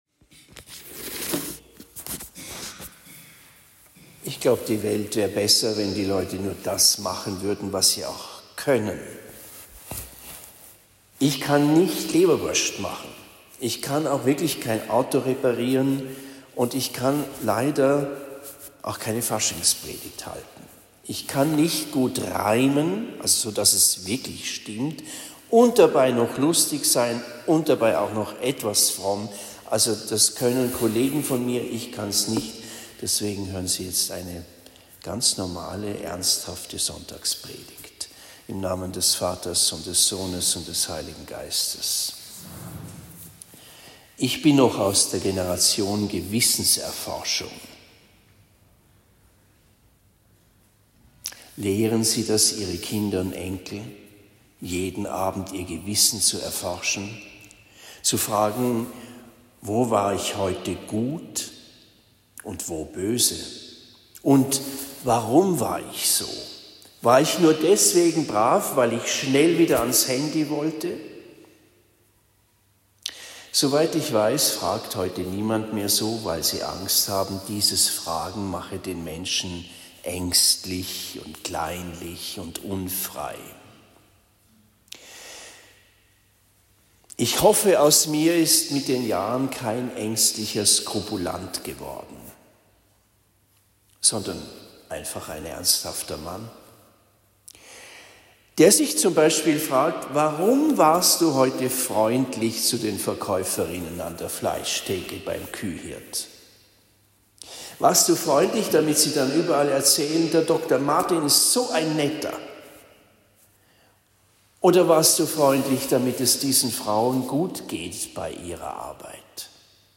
Sechster Sonntag im Jahreskreis (Lesejahr B) – (1 Kor 10,21-11,1) Predigt am 10. Februar 2024 in Esselbach St.-Margaretha und am 11. Februar 2024 in Bischbrunn Heiligste-Dreifaltigkeit